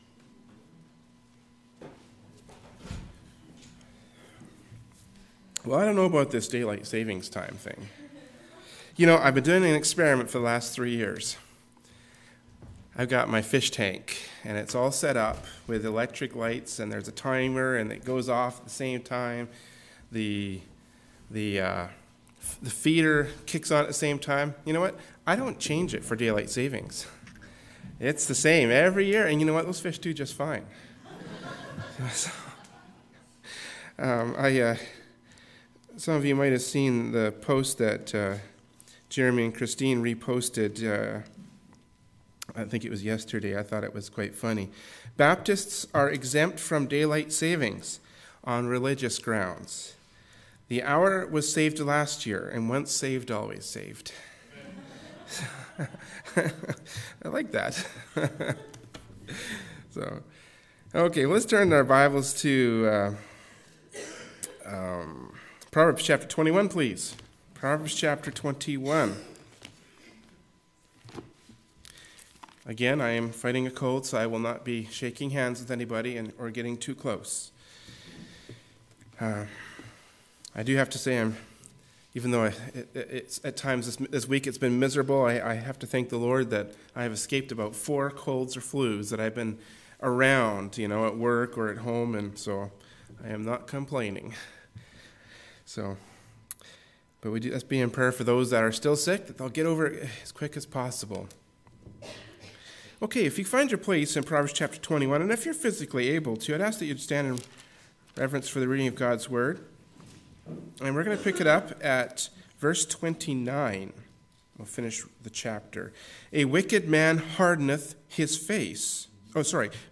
“Proverbs 21:29-31” from Sunday School Service by Berean Baptist Church.
Passage: Proverbs 21:29-31 Service Type: Adult Sunday School